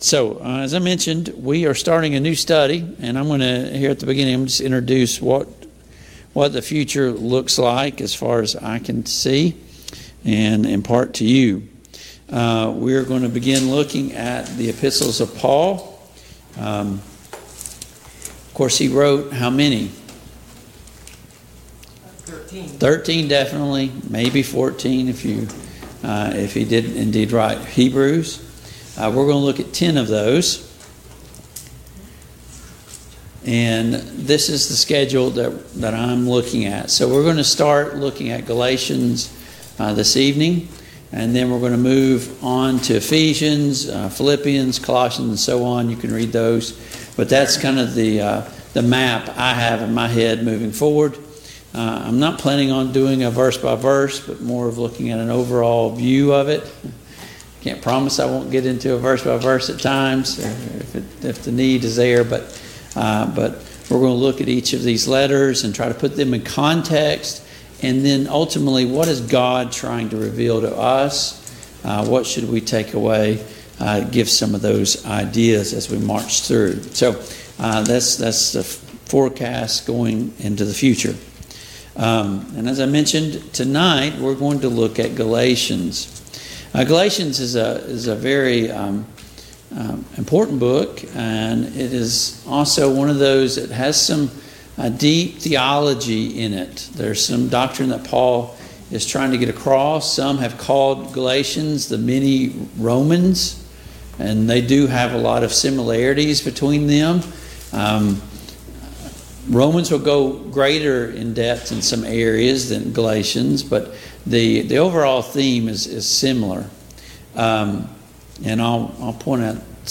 Study of Paul's Minor Epistles Passage: Galatians 1:1-5, Galatians 1:6-9, Galatians 2:19-20 Service Type: Mid-Week Bible Study